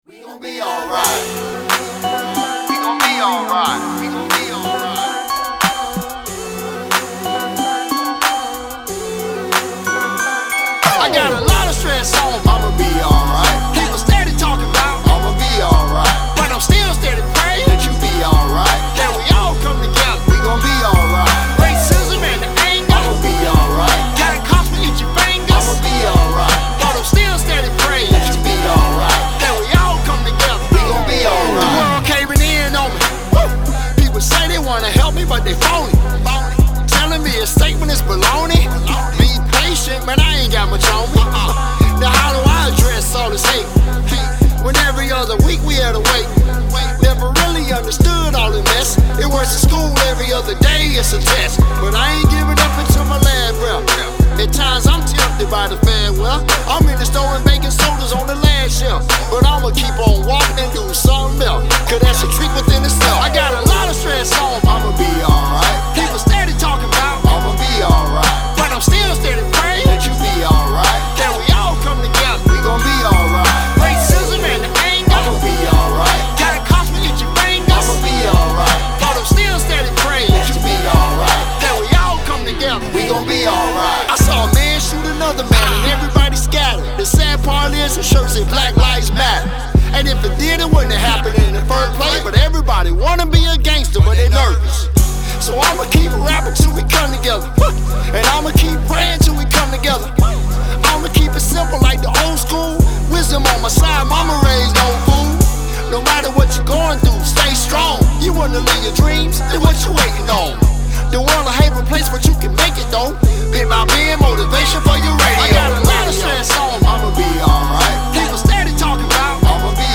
Hiphop
A motivational club banger